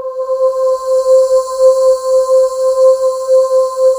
Index of /90_sSampleCDs/USB Soundscan vol.28 - Choir Acoustic & Synth [AKAI] 1CD/Partition C/07-DEEEP